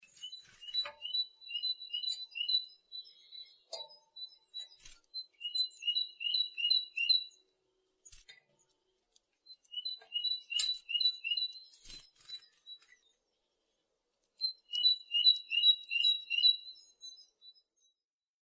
Stimme Kohlmeise
Kohlmeise.mp3